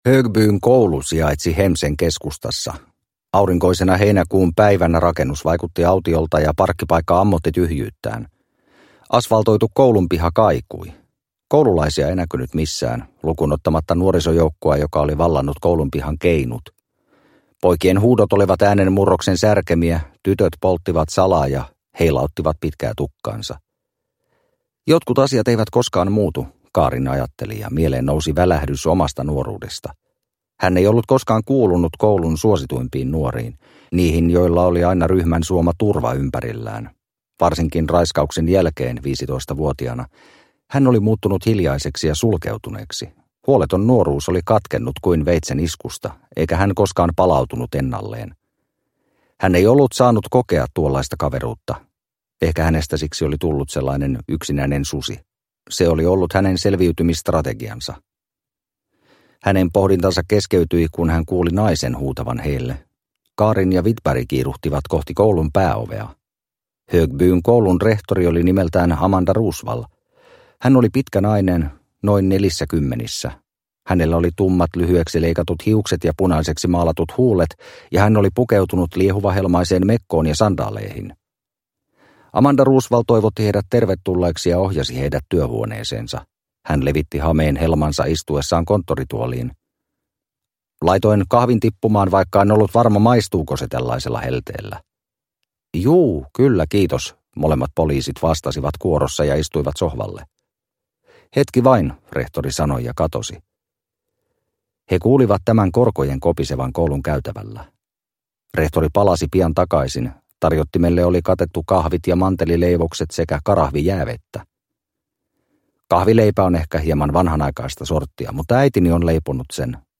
Pimeys keskellämme – Ljudbok – Laddas ner